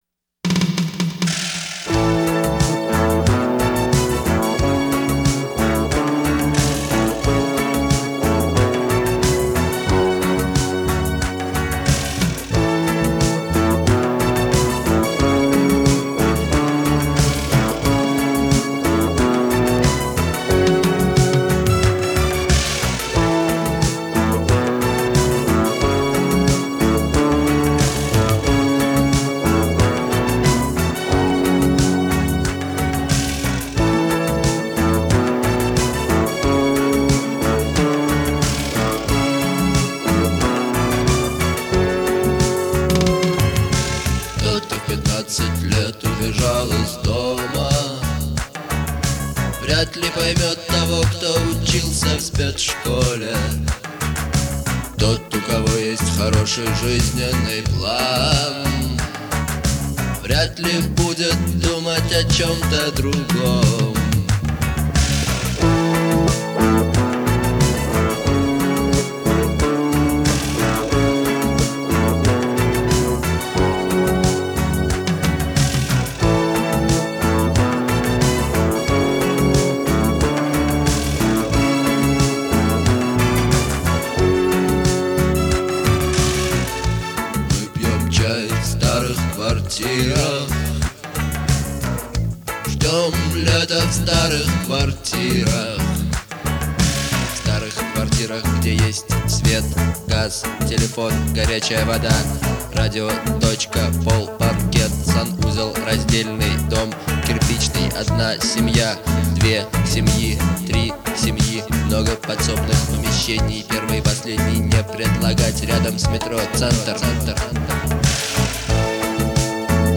это яркий пример русского рок-музыки
мощные гитарные рифы, запоминающаяся мелодия